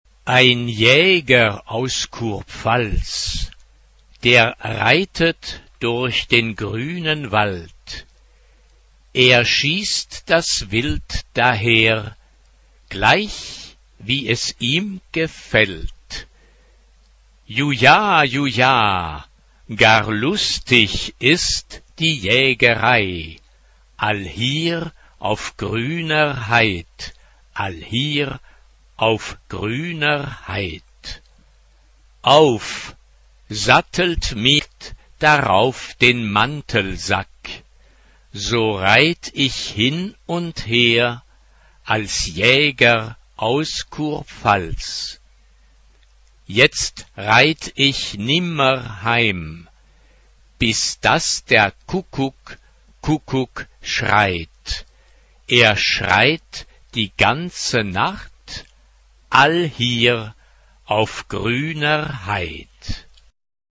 SA OR TB OR 2-part mixed (2 voices women OR men OR mixed) ; Choral score.
Choir. Folk music.
Instrumentation: Piano
Tonality: F major